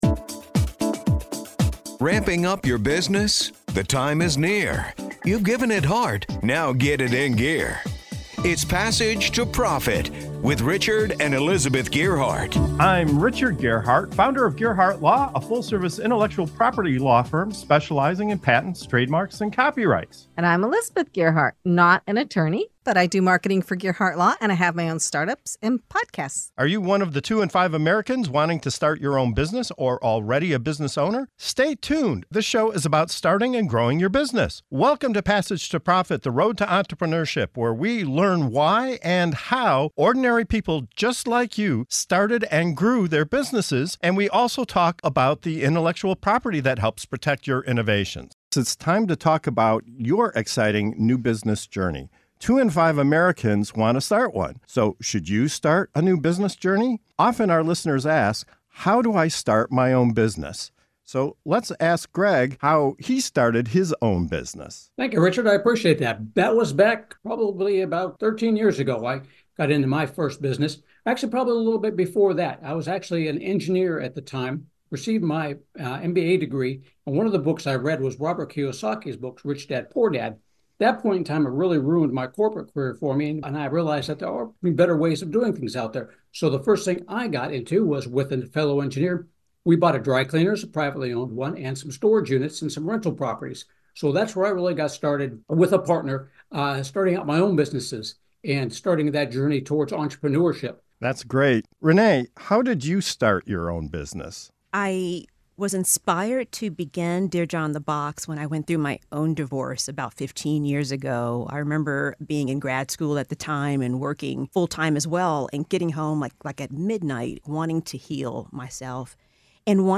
Dive into the captivating stories of entrepreneurship as our guests share their inspiring journeys from corporate careers to entrepreneurial success. From turning personal struggles into thriving ventures to seizing unexpected opportunities, discover the diverse paths that led these innovative minds to carve their own paths in the business world.